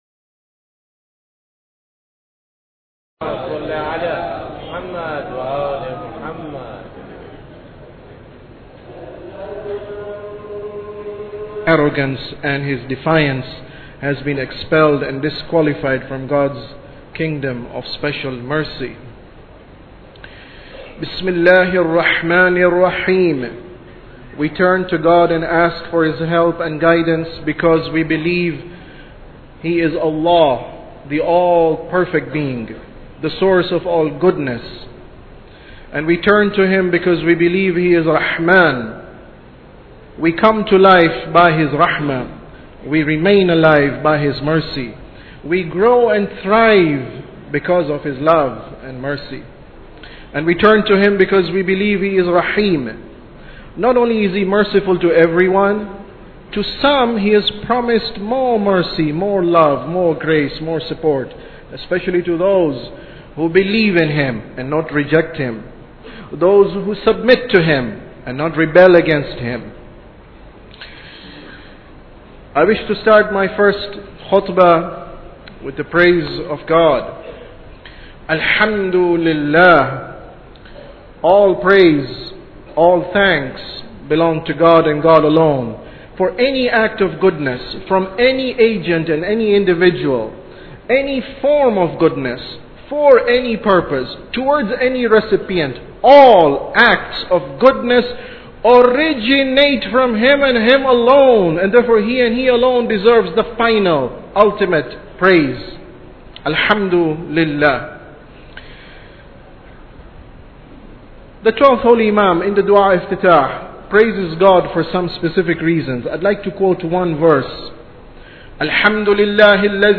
Sermon About Tawheed 5